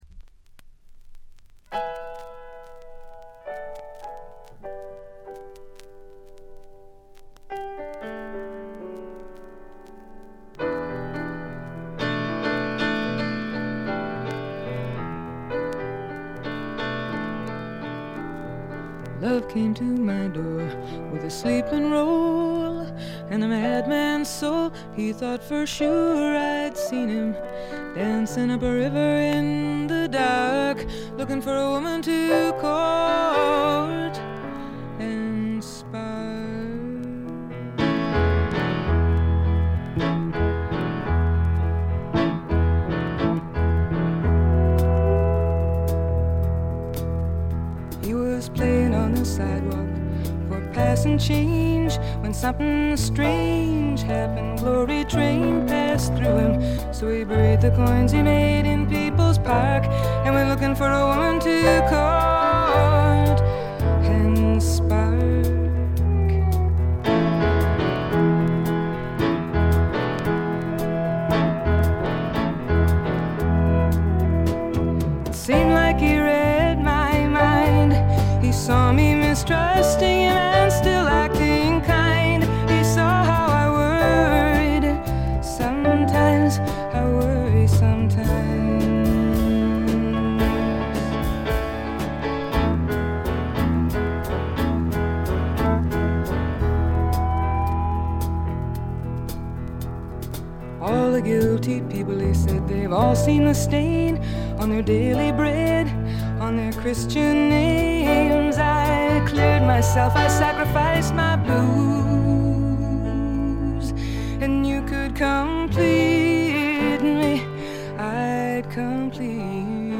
静音部でチリプチが目立つところがありますが（特にA1冒頭とB2）まずまず良好に鑑賞できると思います。
試聴曲は現品からの取り込み音源です。
Chimes